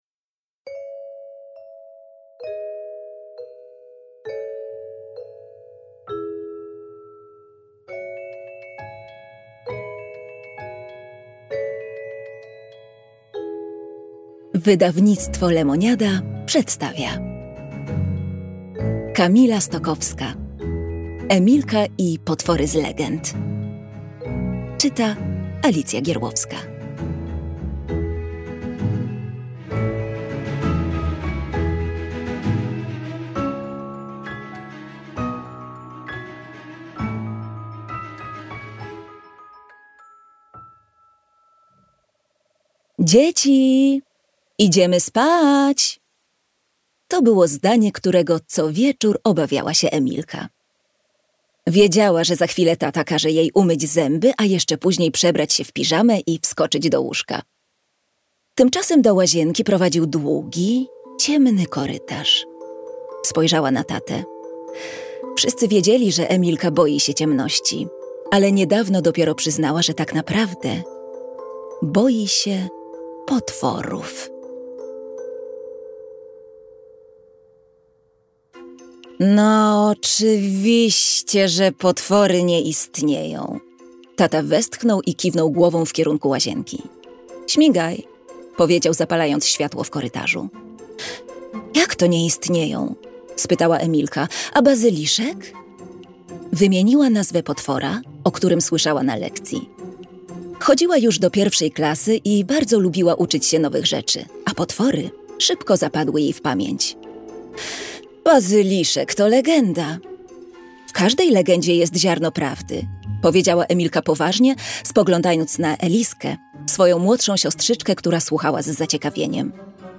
Emilka i potwory z legend - Kamila Stokowska - audiobook